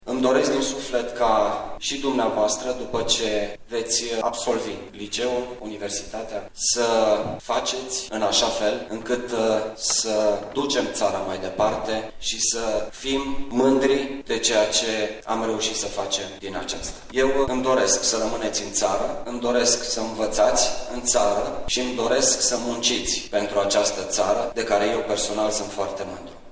Distincția a fost oferită de ministru cu ocazia împlinii a 150 de ani de existență a școlii de elită brașovene.
Ecaterina Andronescu, ministru al Educației: